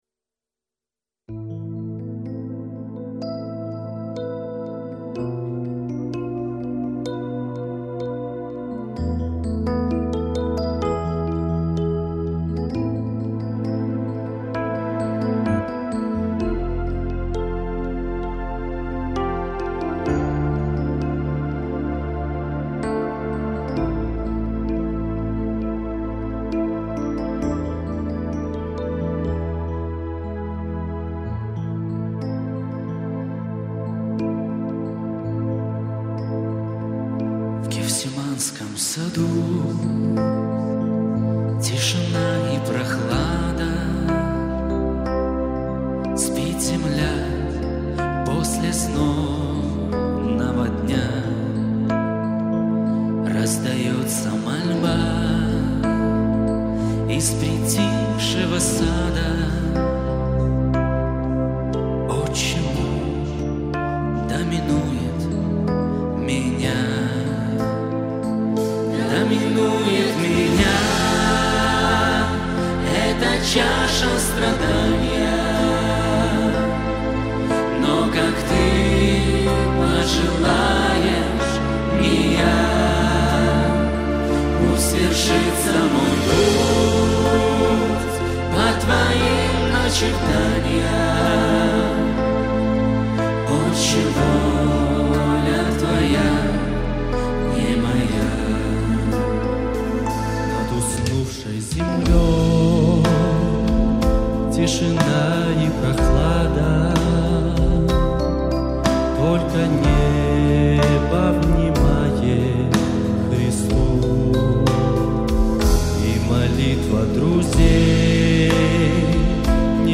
клавиши, гитара, вокал
Псалмы группы